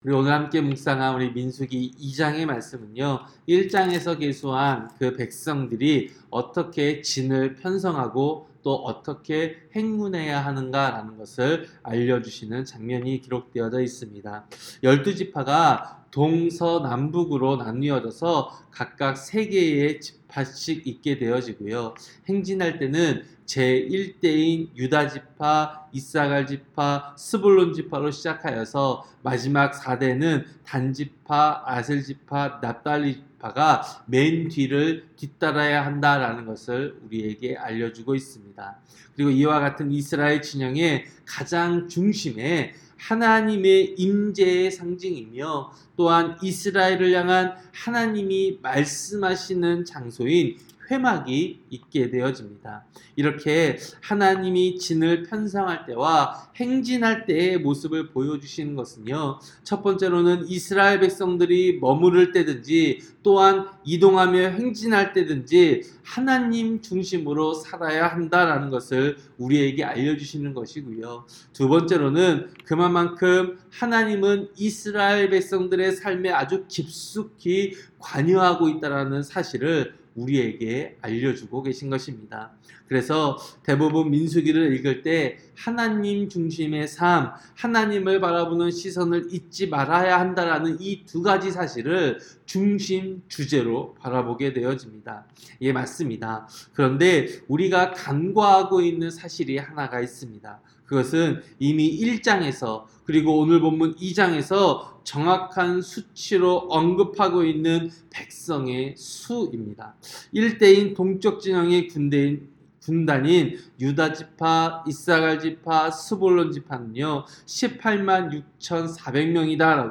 새벽기도-민수기 2장